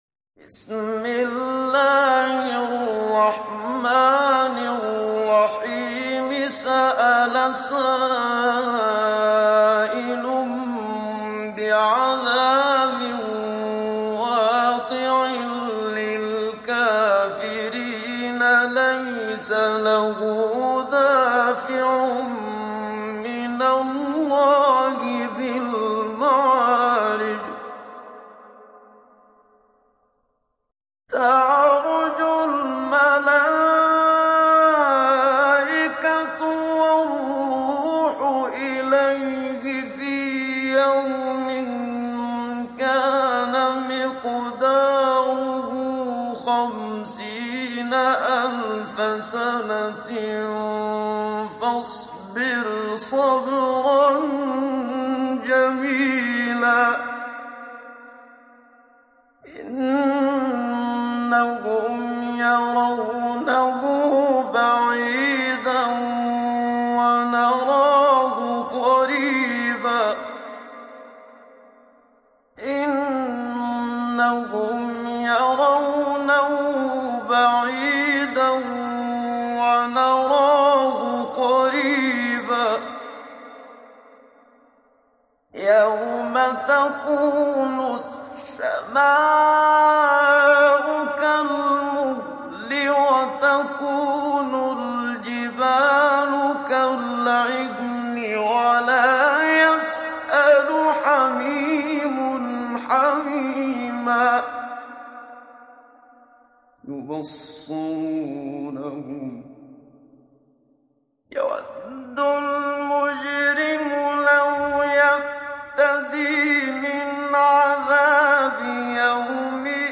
تحميل سورة المعارج mp3 بصوت محمد صديق المنشاوي مجود برواية حفص عن عاصم, تحميل استماع القرآن الكريم على الجوال mp3 كاملا بروابط مباشرة وسريعة
تحميل سورة المعارج محمد صديق المنشاوي مجود